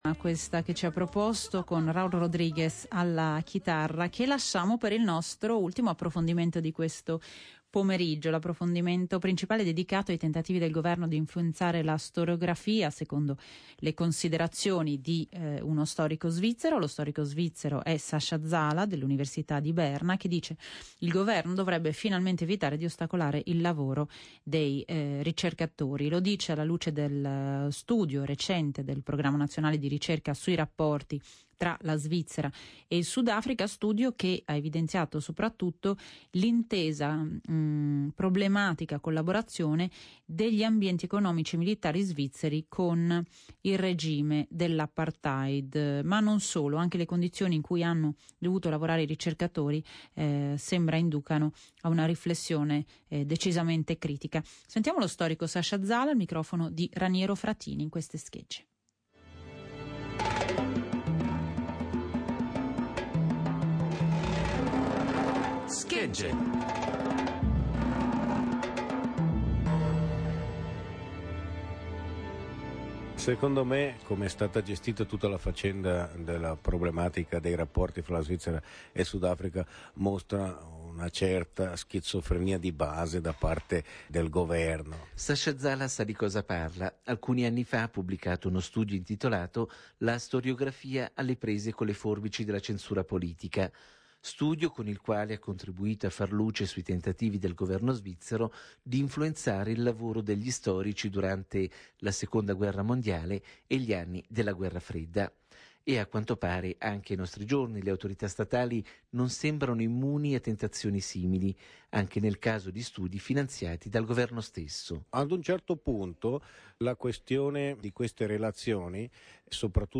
Storia delle relazioni della Svizzera con il Sudafrica, intervista